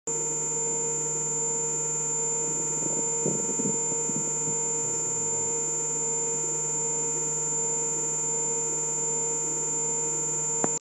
Il s’agit de l’auscultation du bruit fait par un HeartMate II.
La pompe ne fait quasiment aucun bruit même dans un environnement calme, et le patient  ne s’en plaint pas le moins du monde.
Quiz d’auscultation cardiaque
Une auscultation pas fréquente, chez un homme d’une trentaine d’années:
auscultation.mp3